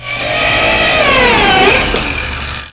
Amiga 8-bit Sampled Voice
powersaw.mp3